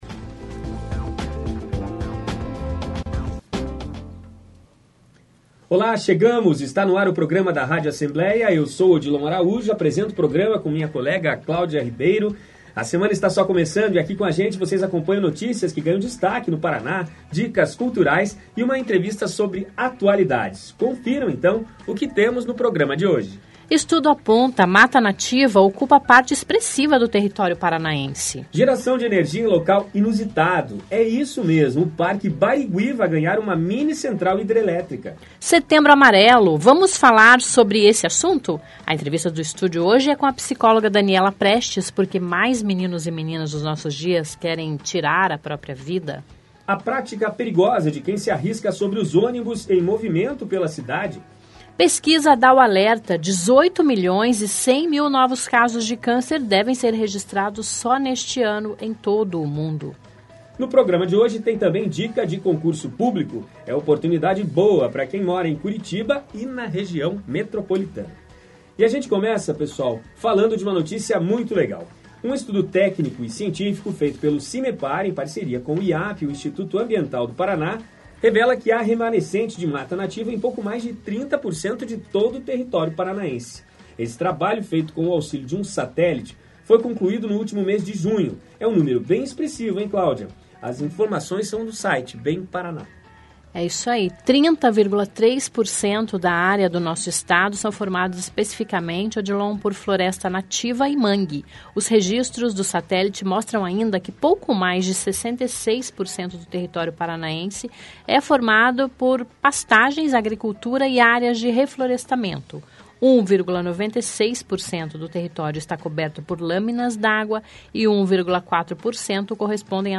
ESTÁ NO AR O PROGRAMA DA RÁDIO ASSEMBLEIA.